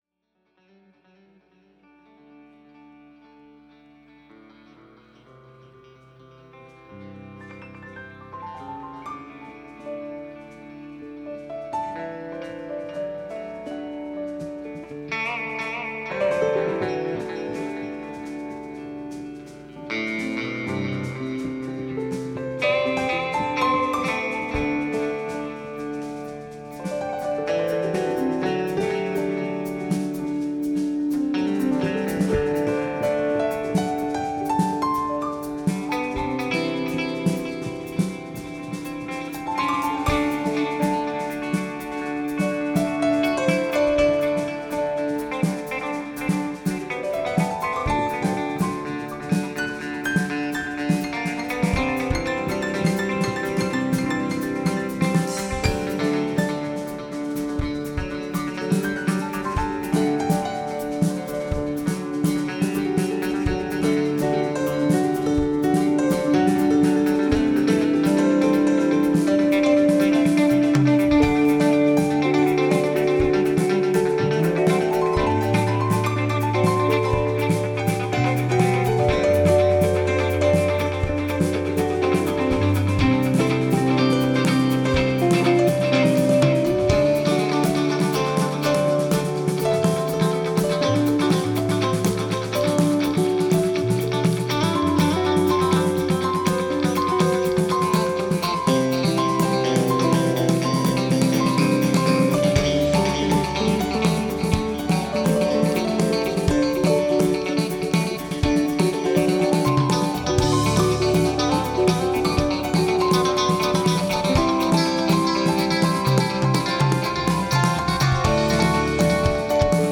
This show is at a cool festival and these guys can jam.
Keys / Vox
Guitar / Vox
Bass / Vox
Drums / Vox Source: SBD Matrix